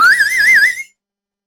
Slide Whistle
A playful slide whistle gliding up and down in pitch for comedic effect
slide-whistle.mp3